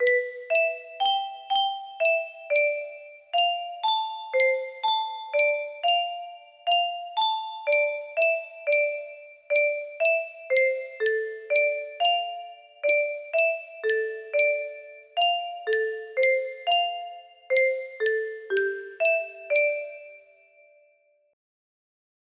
BEGGED FACADEs FADED DECADEs DEAD FACEs CAGED music box melody